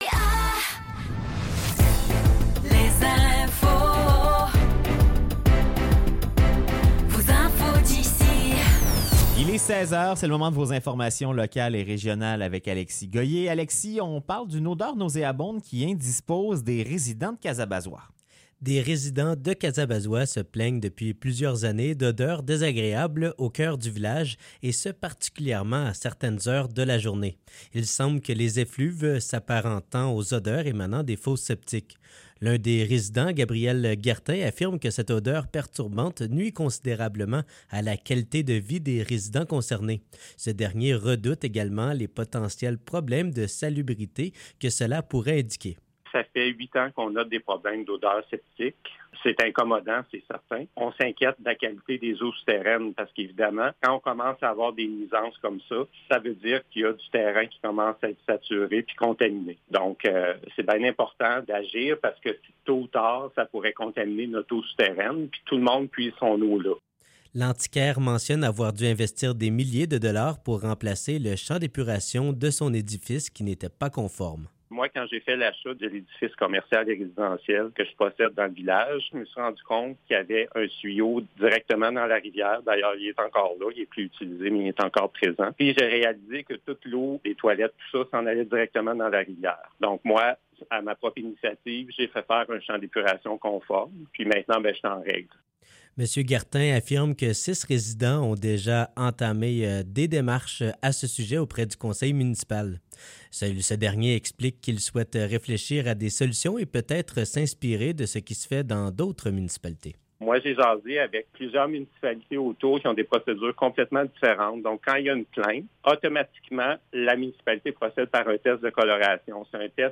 Nouvelles locales - 30 janvier 2024 - 16 h